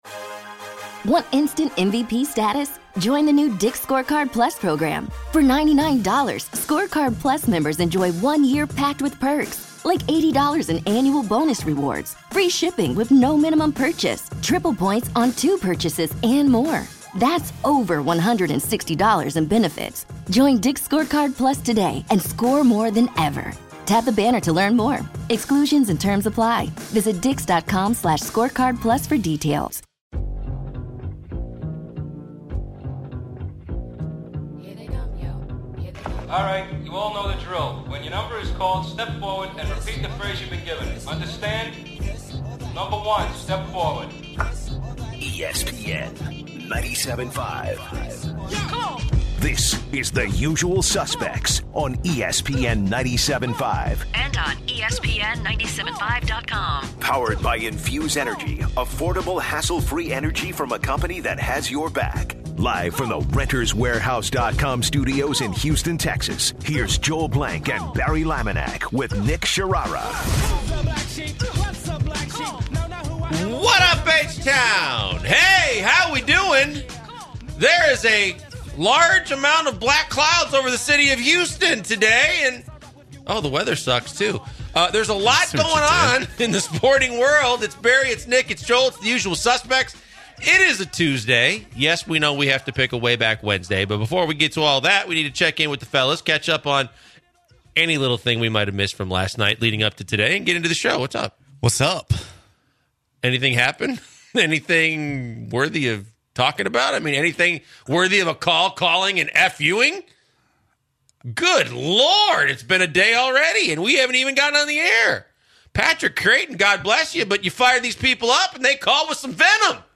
Also the guys take in multiple callers who have varying opinions on the Osuna trade.